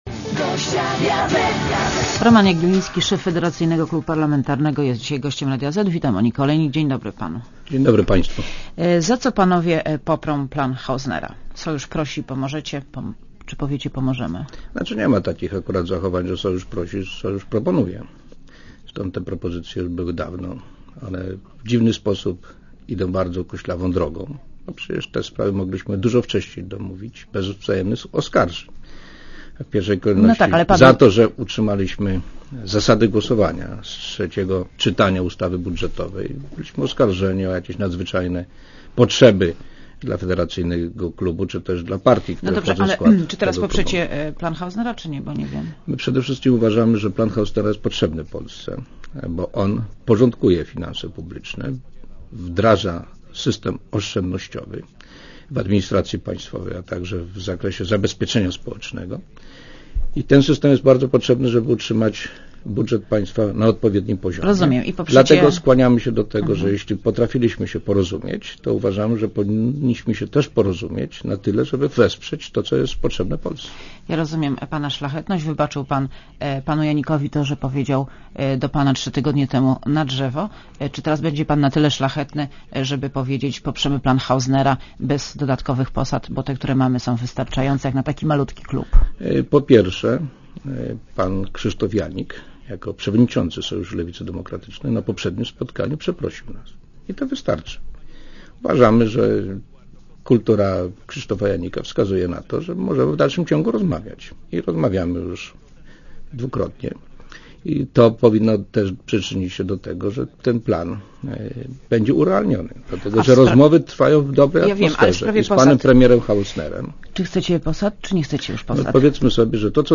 Posłuchaj wywiadu (1,98Mb) Roman Jagieliński, szef Federacyjnego Klubu Parlamentarnego jest dzisiaj gościem Radia Zet.